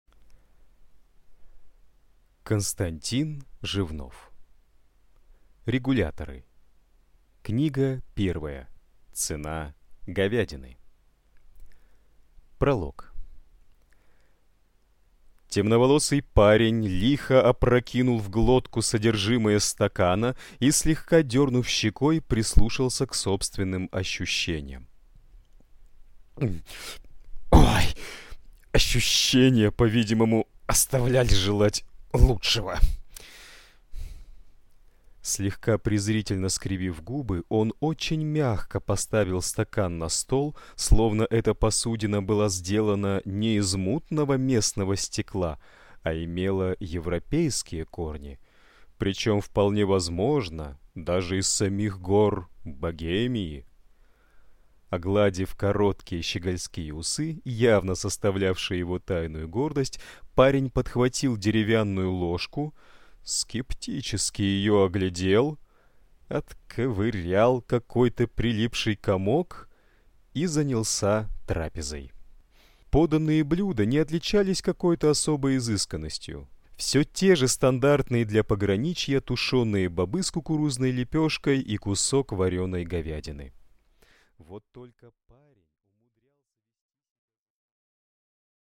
Аудиокнига Регуляторы. Книга первая. Цена говядины | Библиотека аудиокниг